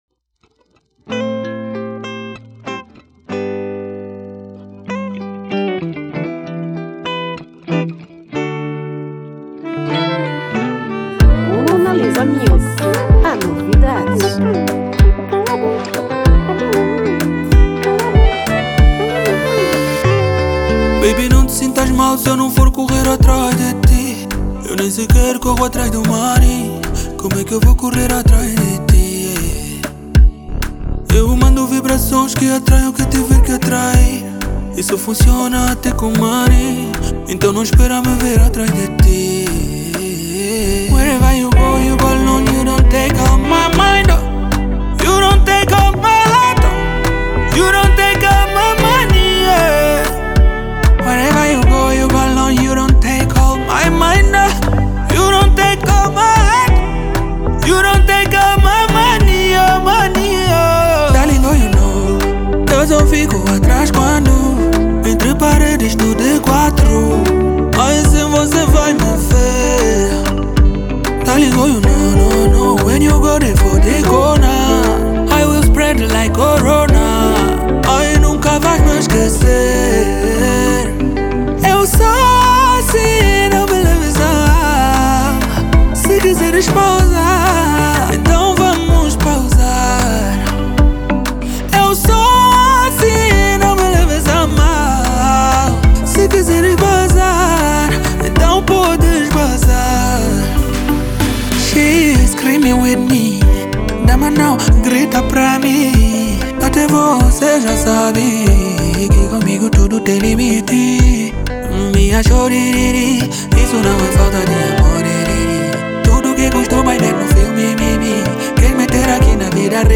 Gênero : Zouk